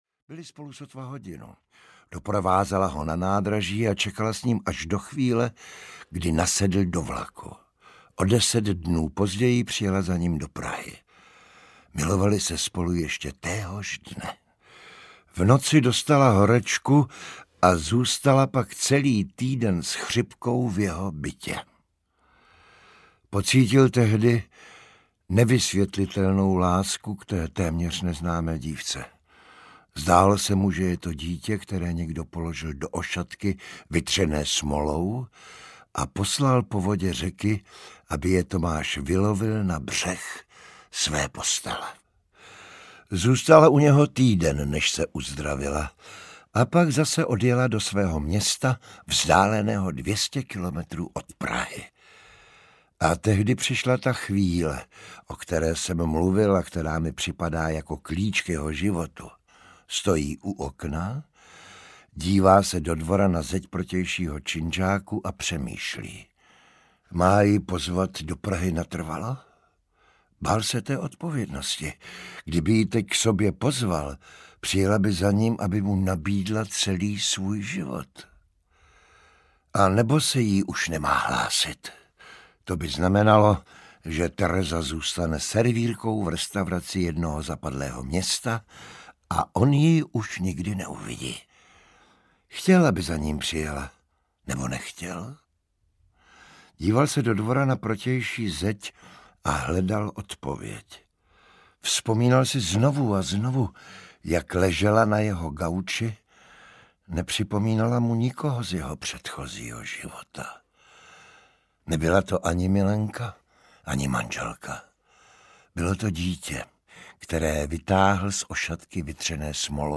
Nesnesitelná lehkost bytí audiokniha
Ukázka z knihy
• InterpretJiří Bartoška